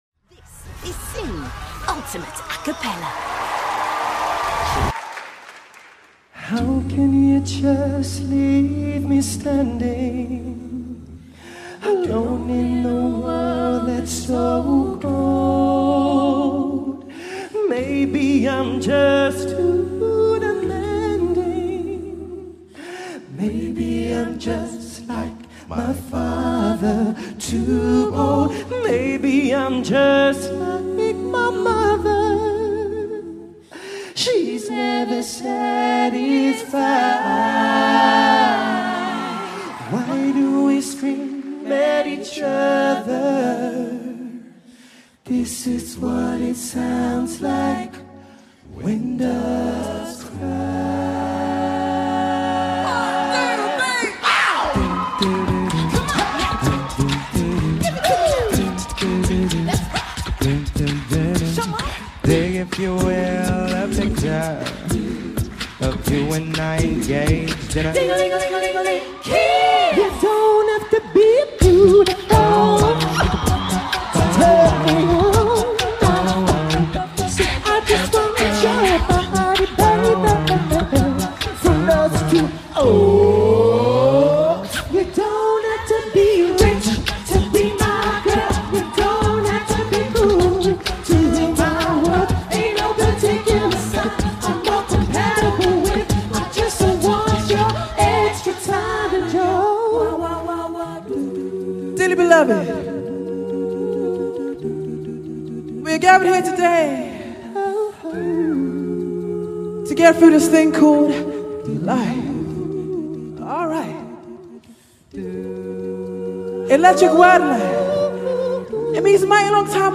• Can sing completely acapella
either a cappella or alongside professional backing tracks.